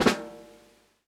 DoubleSnr.wav